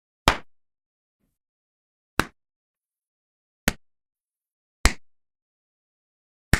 Bofetadas (varias)
bofetada
Sonidos: Acciones humanas